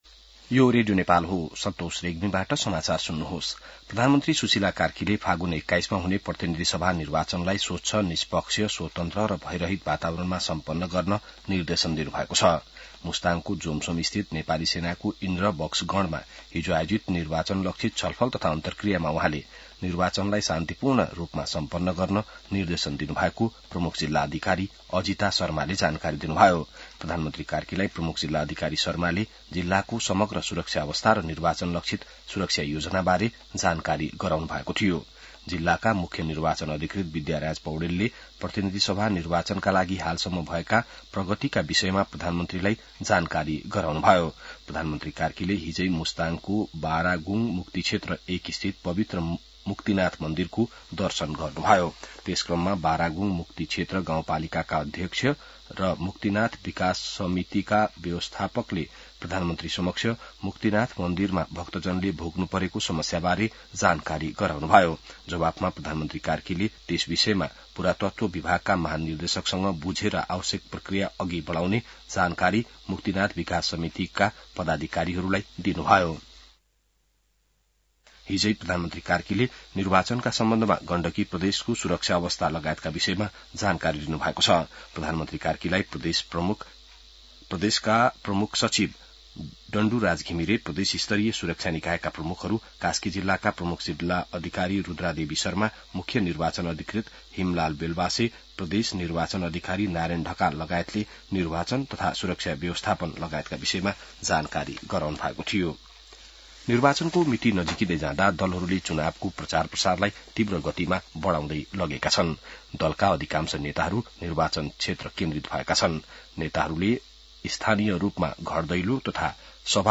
बिहान ६ बजेको नेपाली समाचार : ९ फागुन , २०८२